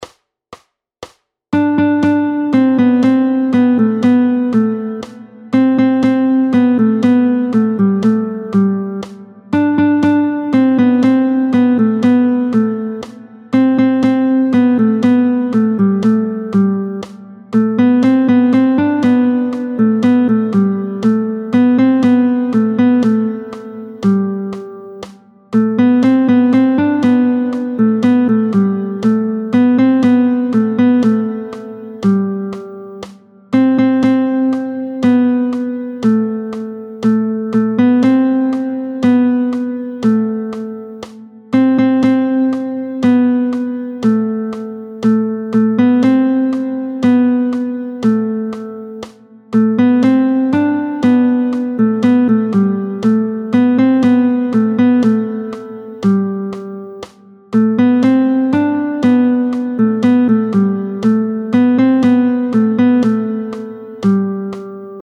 √ برای ساز گیتار | سطح آسان